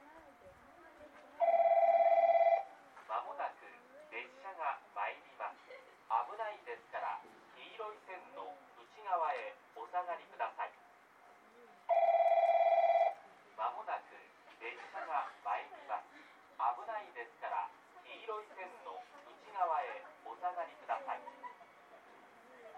この駅では接近放送が設置されています。
接近放送普通　宮崎行き接近放送です。